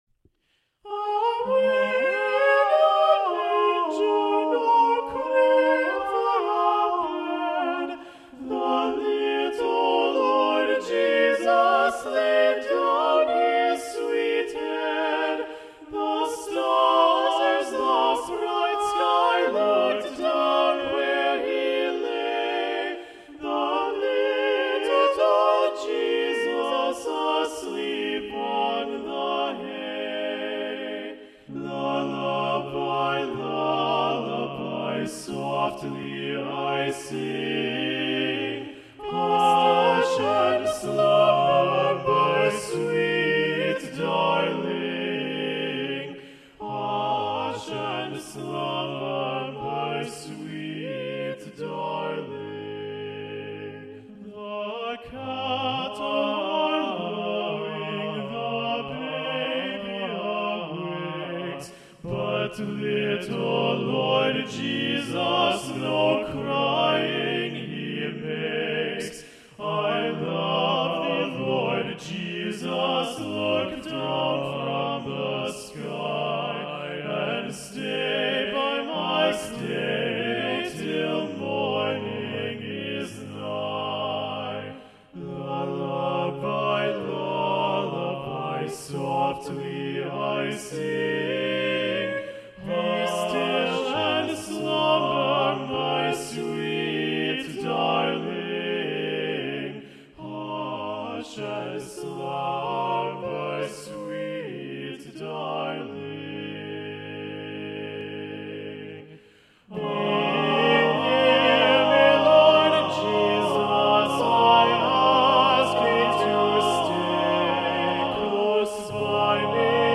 Choral Music
HOLIDAY MUSIC — A CAPPELLA